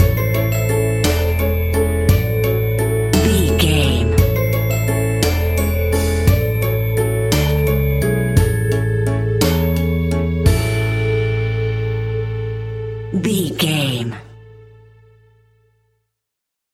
Ionian/Major
fun
childlike
cute
happy
kids piano